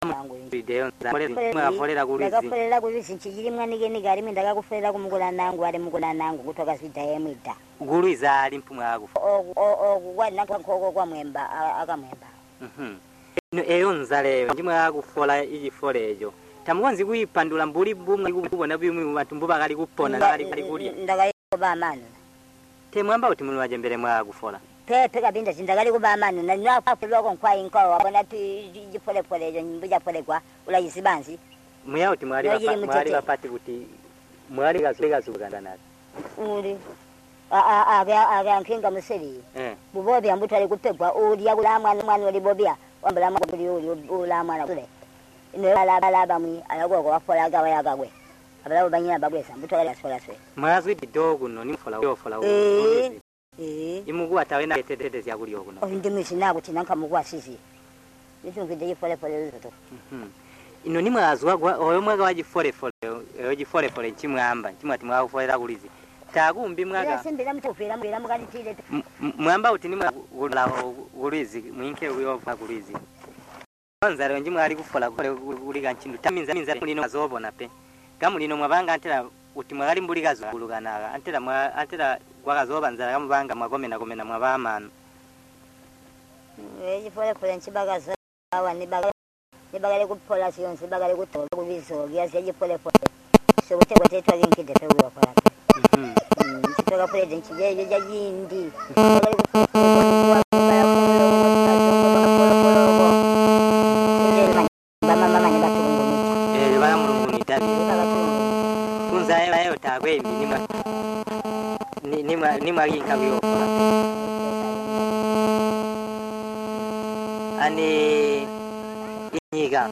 Tonga Oral History